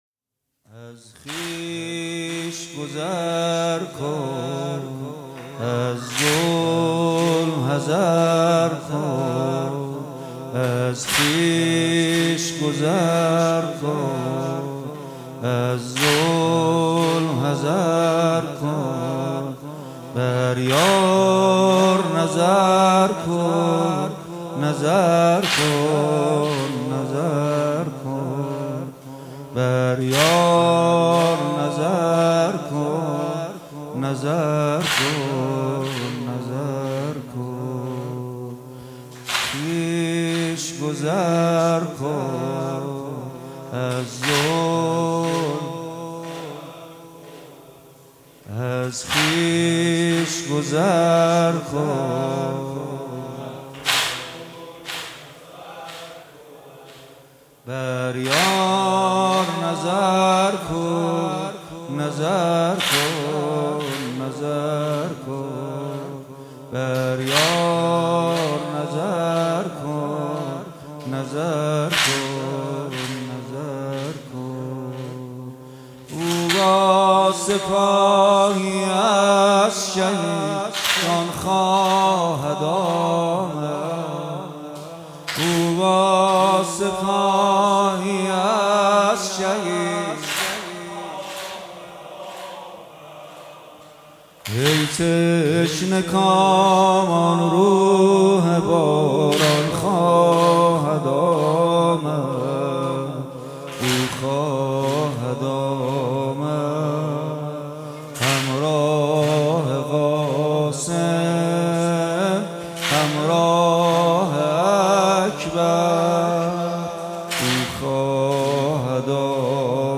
1403 اجراشده. مداحی به سبک واحد اجرا شده است.